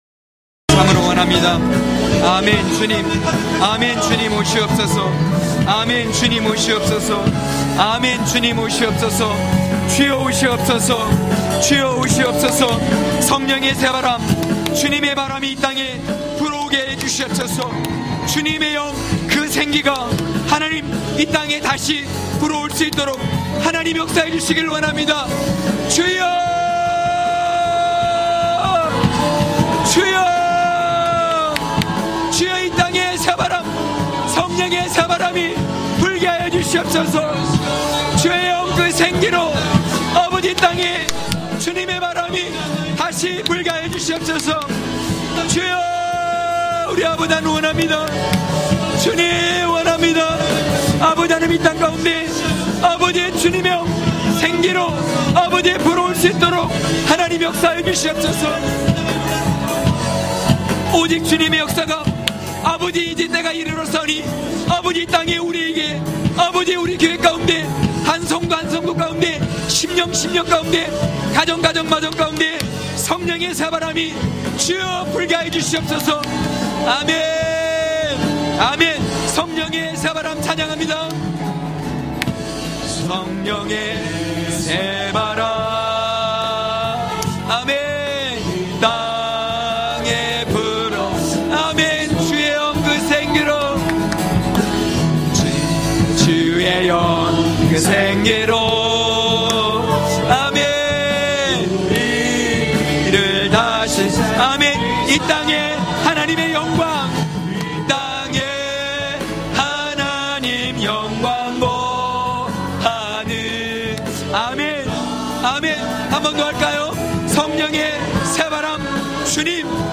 강해설교 - 6.하나님의 때가 찬 역사...(느4장1~6절).mp3